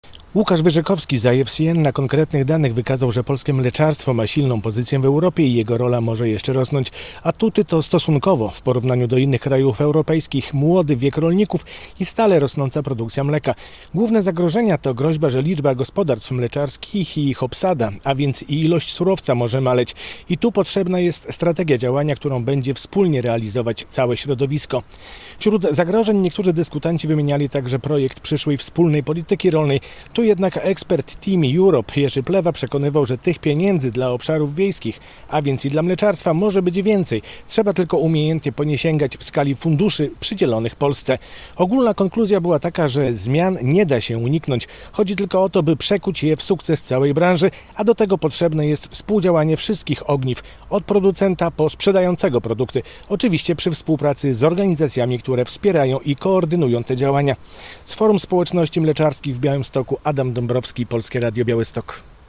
Polskie mleczarstwo a Unia Europejska - relacja